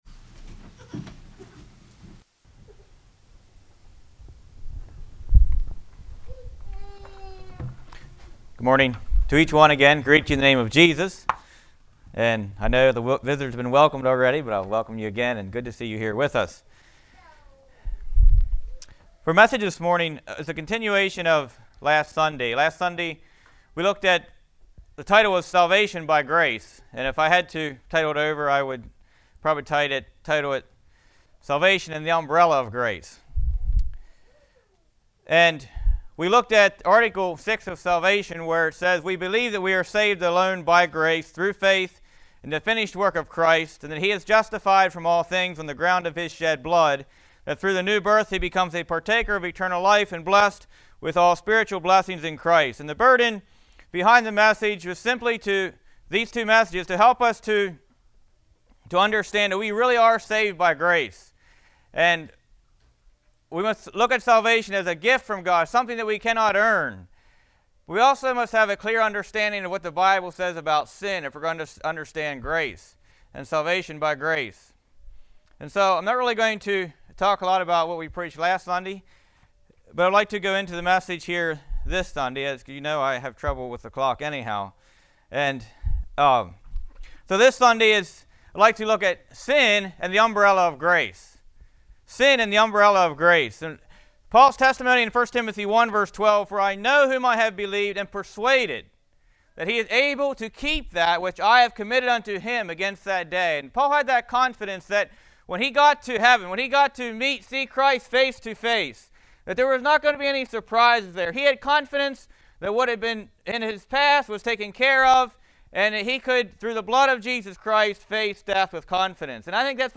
Congregation: Winchester